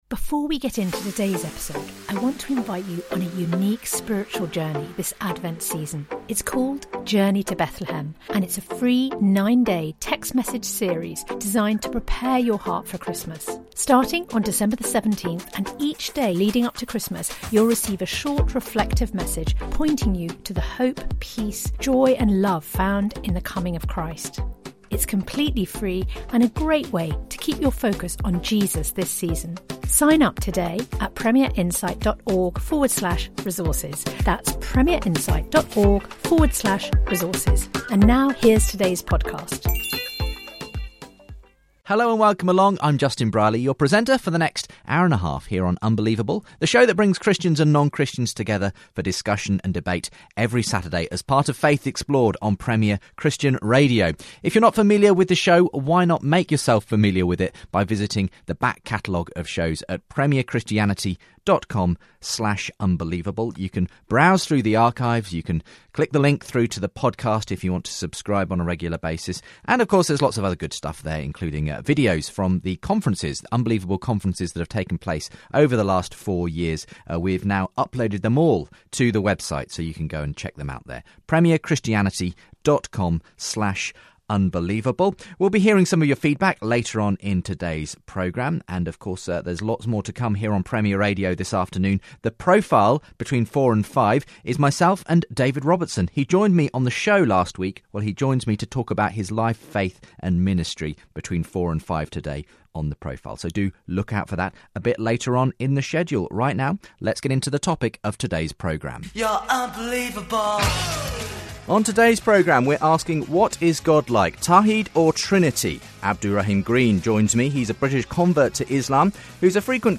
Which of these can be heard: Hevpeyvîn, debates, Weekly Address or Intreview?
debates